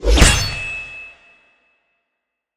Sword Contact (with swipe)
anime ding film foley hit impact knife metal sound effect free sound royalty free Movies & TV